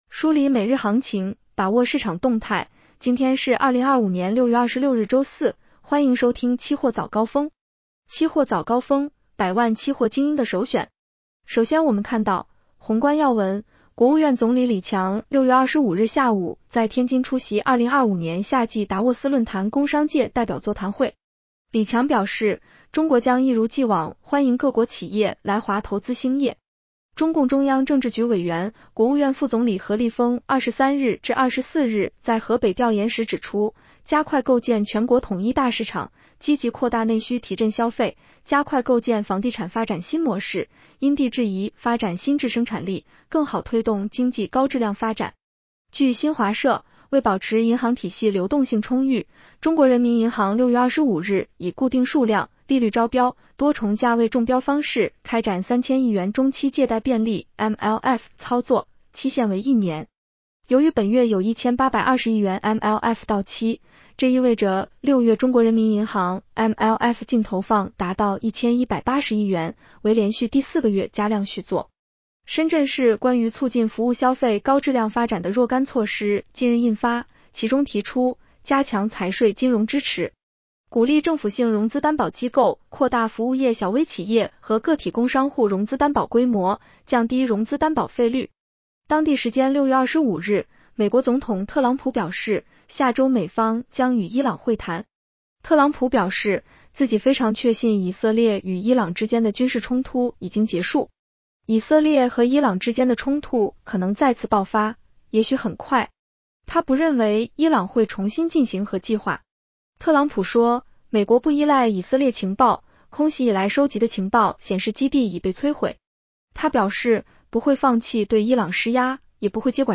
期货早高峰-音频版
期货早高峰-音频版 女声普通话版 下载mp3 宏观要闻 1.